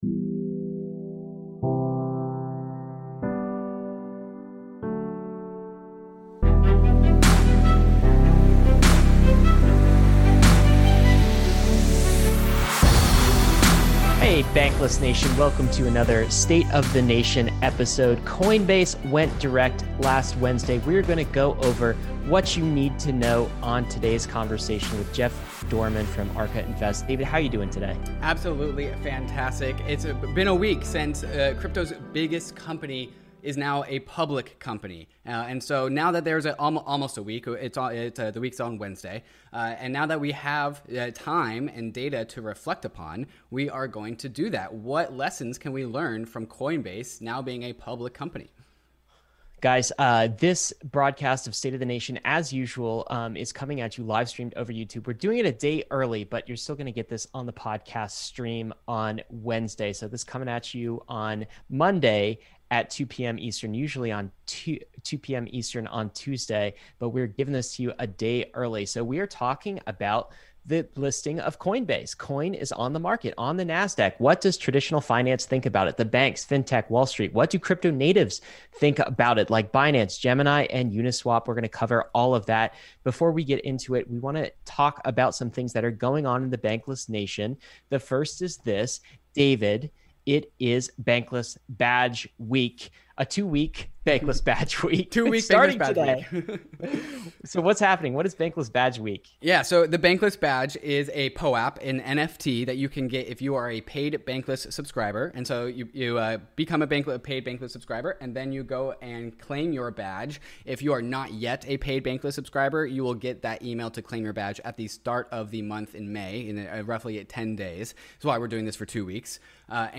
State of the Nation is live-streamed on Tuesdays at 11am PST.